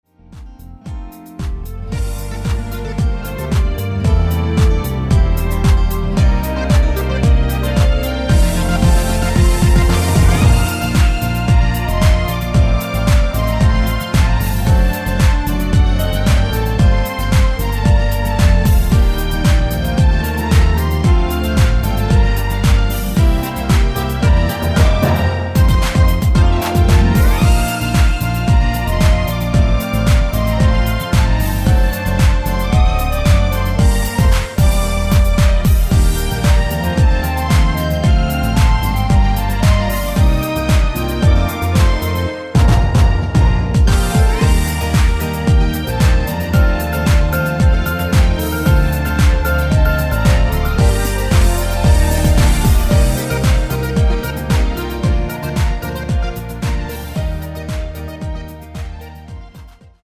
멜로디포함된 MR 입니다.
원곡의 보컬 목소리를 MR에 약하게 넣어서 제작한 MR이며